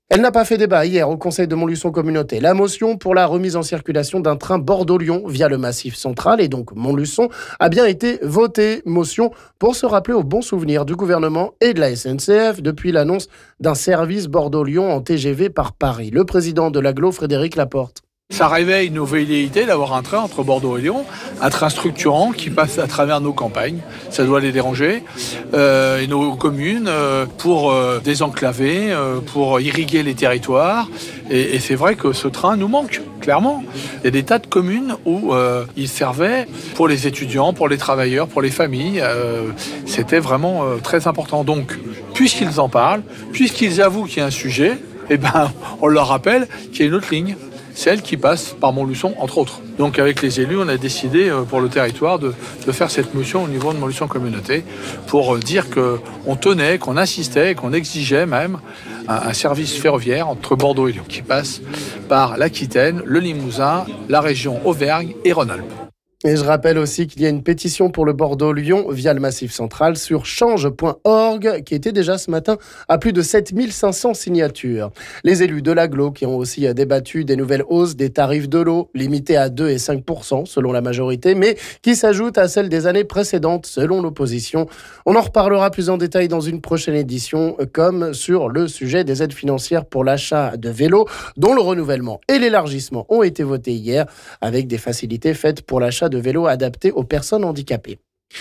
On écoute ici le président de l'agglo Frédéric Laporte...